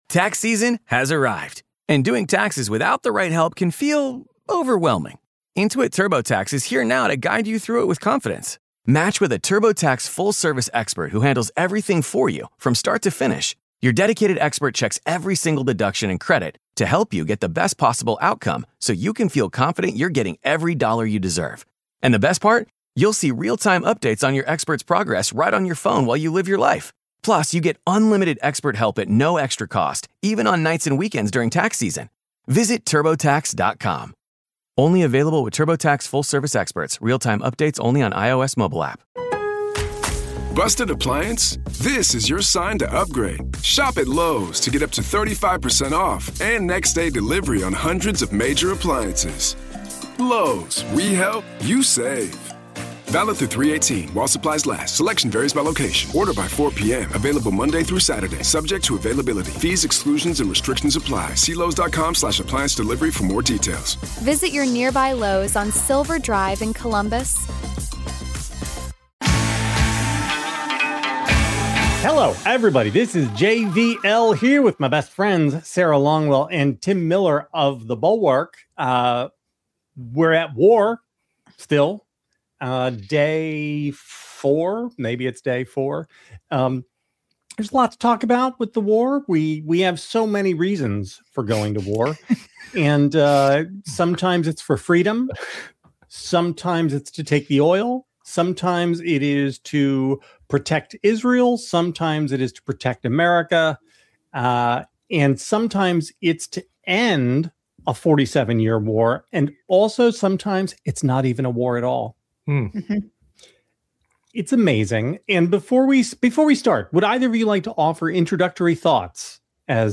Sarah Longwell, JVL, and Tim Miller are going live to talk about the shifting mood around the Iran strikes—how casualties could change the politics, whether 2026 is starting to feel like 2006, and if Trump’s “just do stuff” approach has hit a wall (with JD already hedging). Also: the latest Democratic freakout over Graham Platner’s campaign.